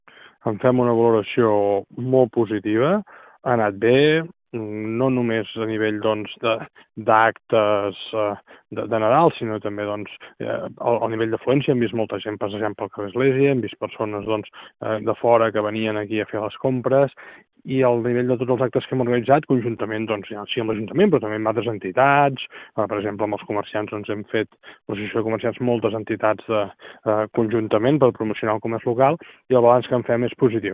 En declaracions a Ràdio Calella TV, l’alcalde Marc Buch feia una valoració positiva de la resposta dels calellencs i calellenques a les activitats que es van programar per gaudir del Nadal.